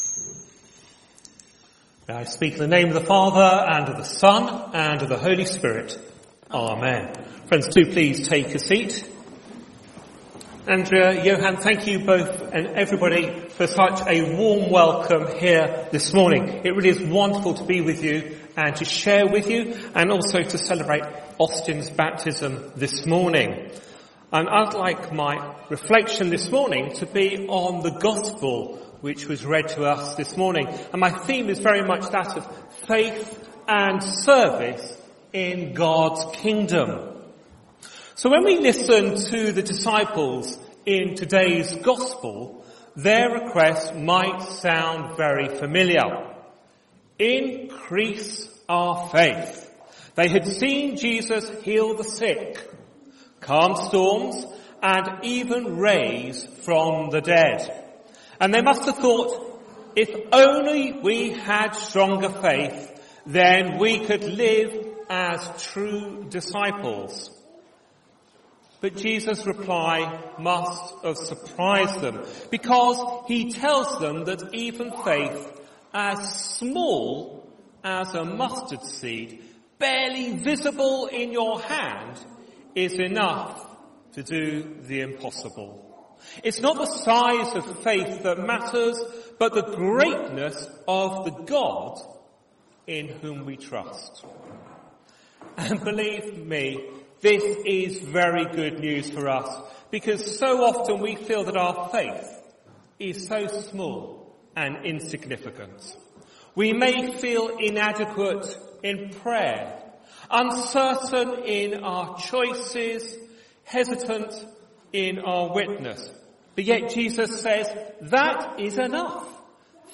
HomeSermonsServe faithfully and grow in…